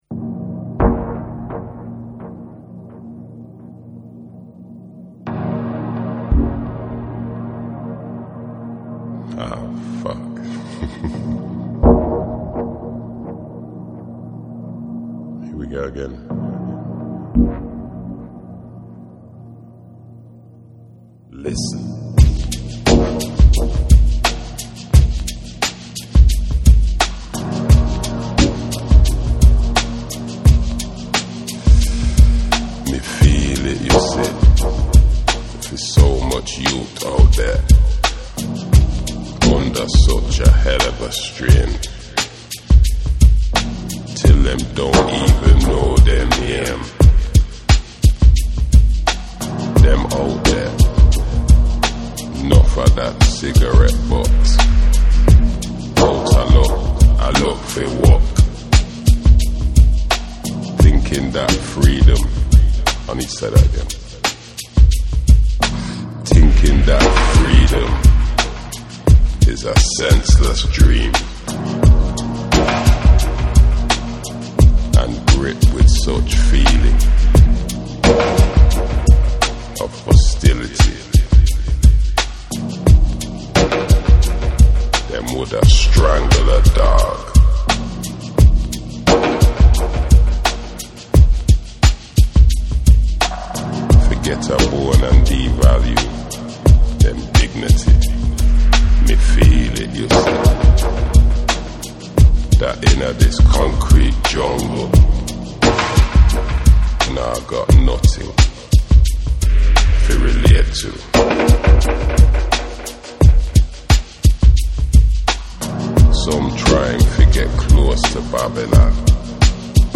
両面ともに彼ららしいモノクロームな質感の、ディープな空間が広がるダブ・サウンドを聴かせている。